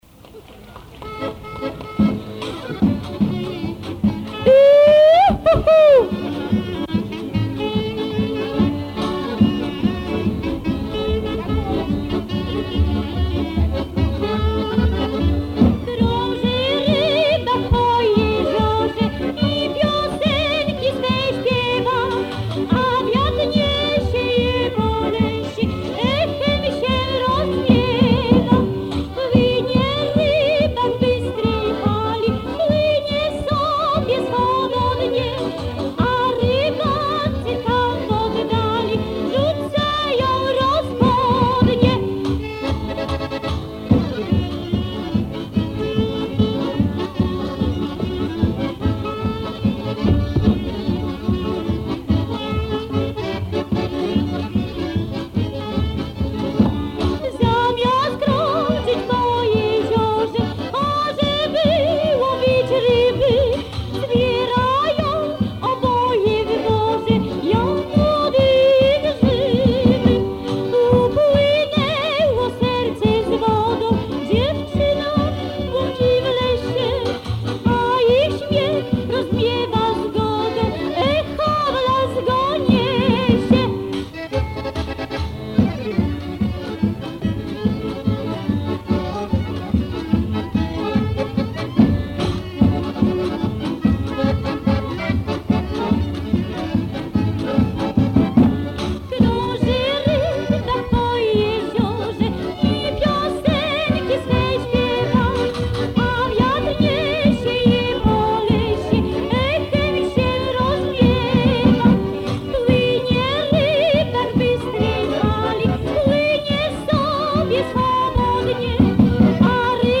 Quatorzième pièce - Voix lyrique accompagnée
Pièce musicale éditée